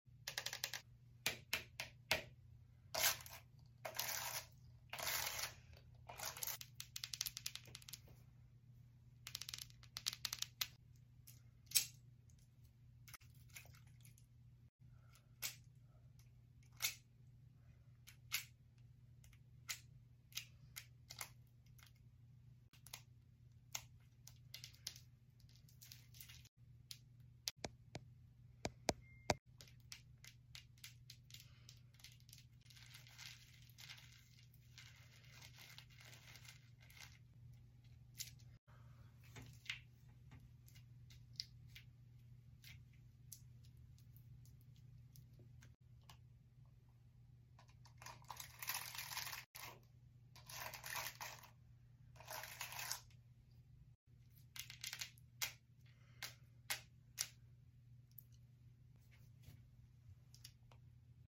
Knox gelatin hair ASMR 👨‍🍳💋 sound effects free download